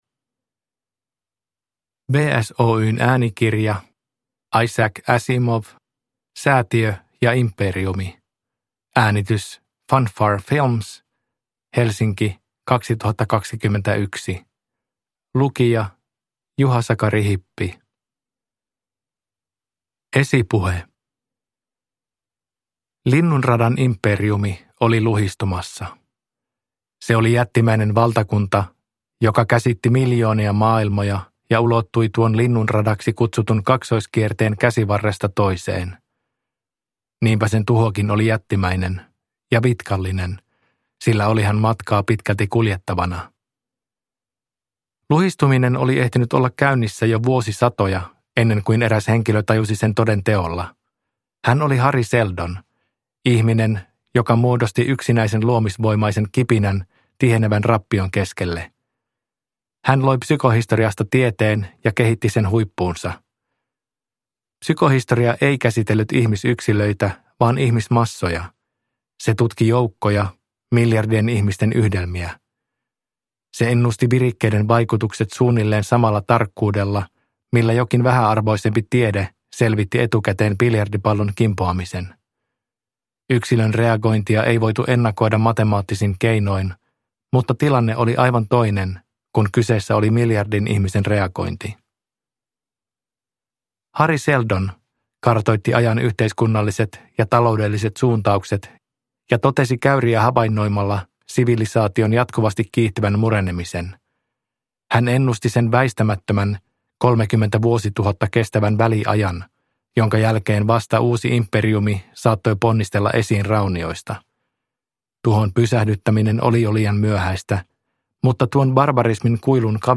Säätiö ja Imperiumi – Ljudbok – Laddas ner
Huomautus kuuntelijoille: äänikirjassa esiintyvät otteet Galaktisesta ensyklopediasta päättyvät vaimennukseen.